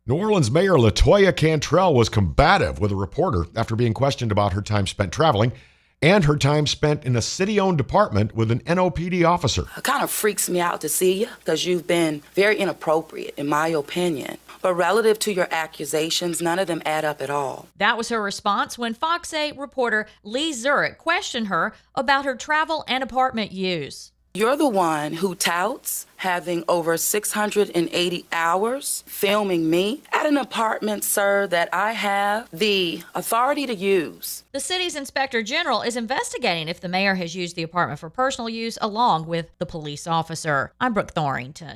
New Orleans Mayor LaToya Cantrell answered questions Wednesday about issues surrounding her leadership.